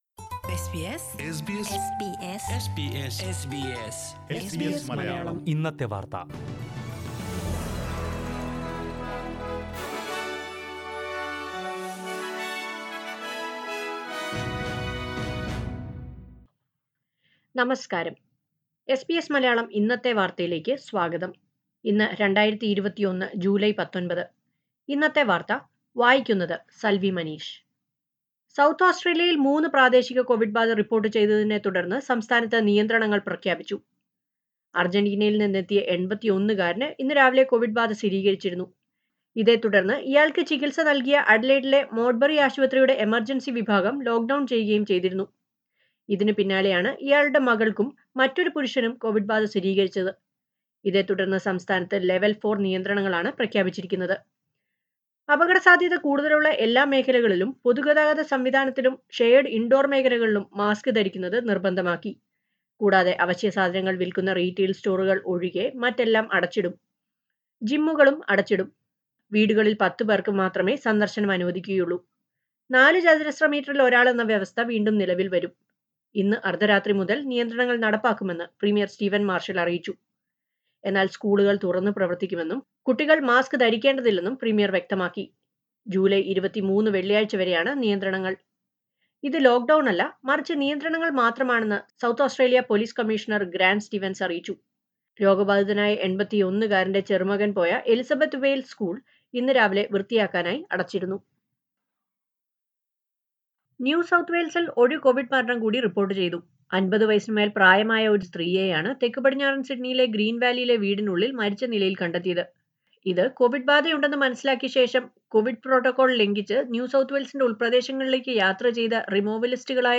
2021 ജൂലൈ 19ലെ ഓസ്ട്രേലിയയിലെ ഏറ്റവും പ്രധാന വാർത്തകൾ കേൾക്കാം...